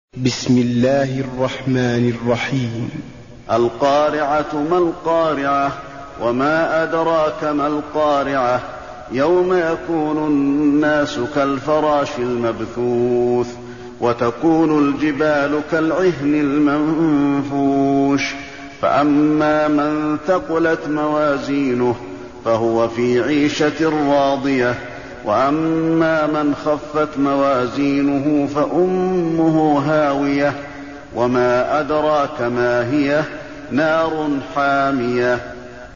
المكان: المسجد النبوي القارعة